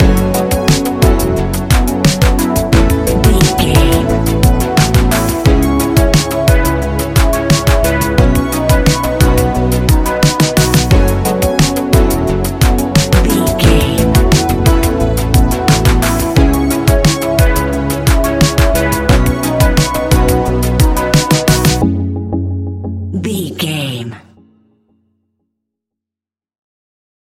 Ionian/Major
F♯
ambient
electronic
new age
downtempo
synth
pads